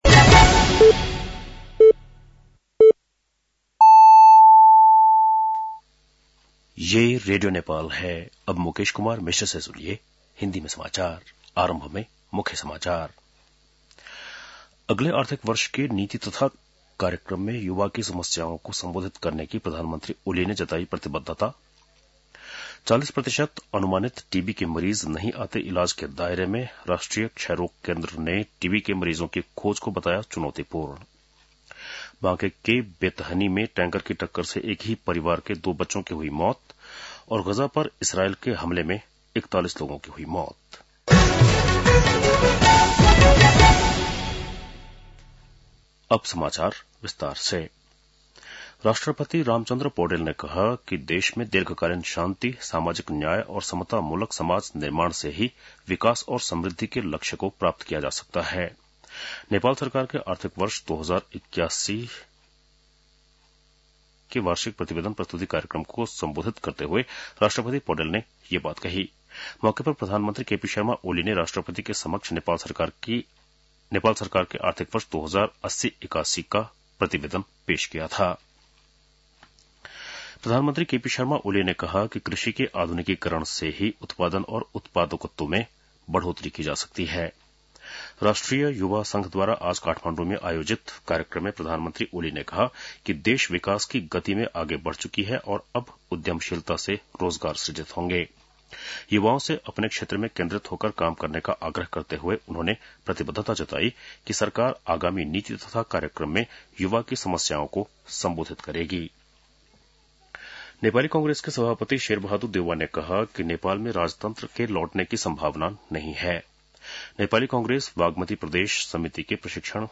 बेलुकी १० बजेको हिन्दी समाचार : १० चैत , २०८१